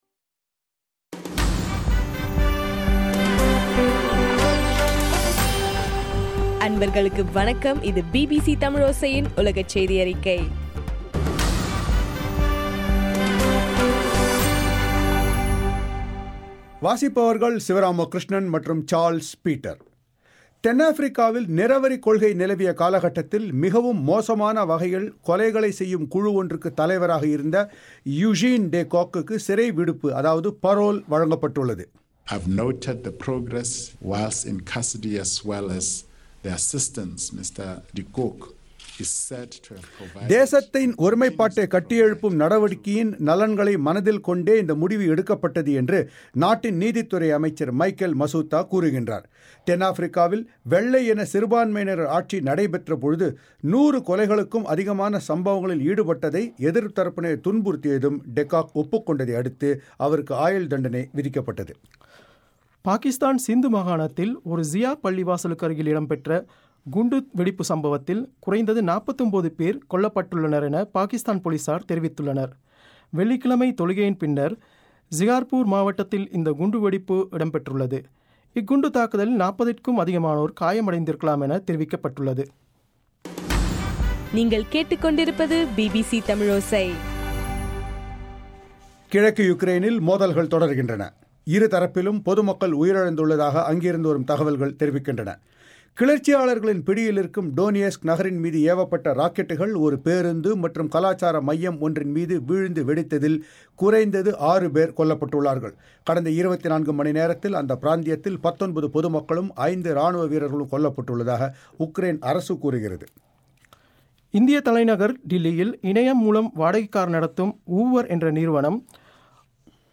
ஜனவரி 20 பிபிசியின் உலகச் செய்திகள்